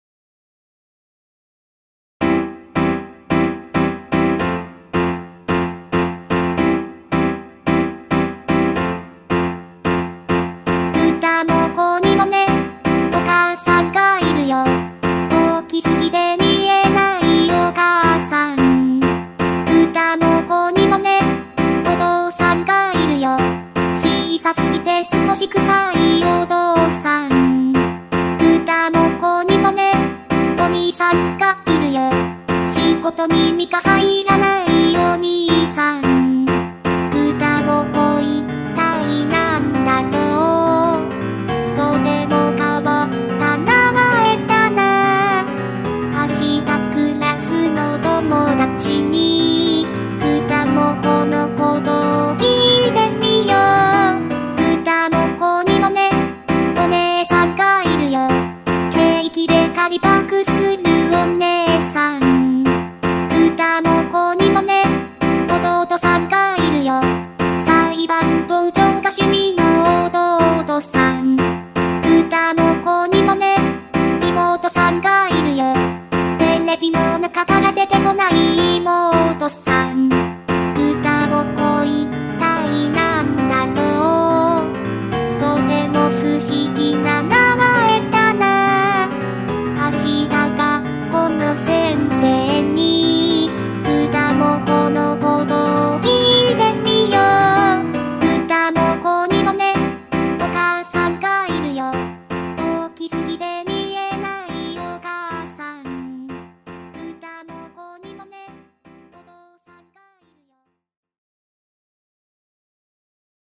歌: 初音ミク